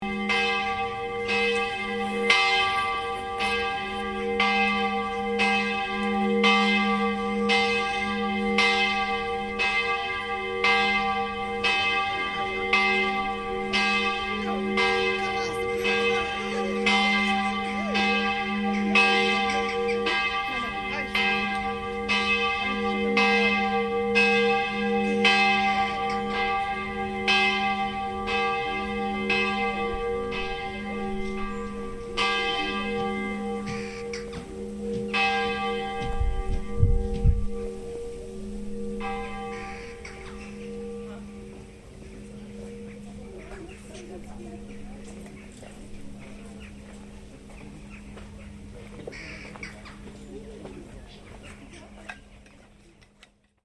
From the top of a high viewpoint in the incredibly picturesque Tuscan town of San Gimignano, Italy, late afternoon bells ring out in the golden sunshine.